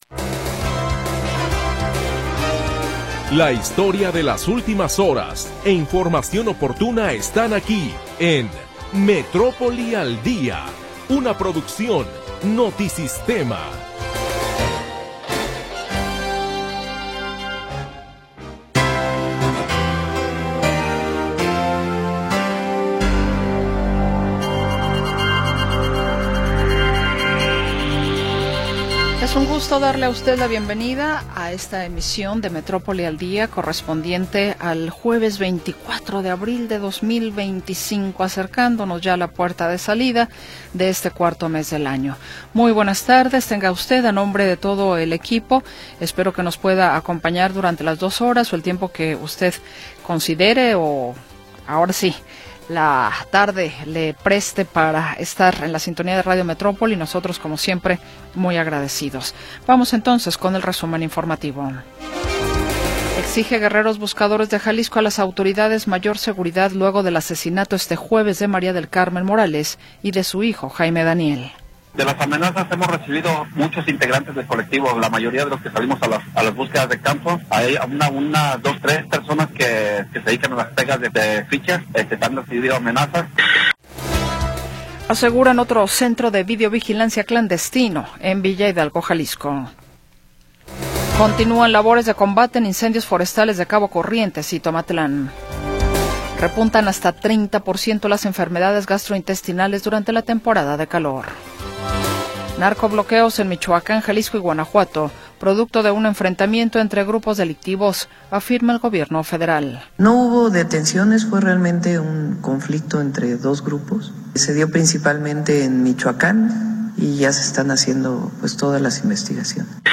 La historia de las últimas horas y la información del momento. Análisis, comentarios y entrevistas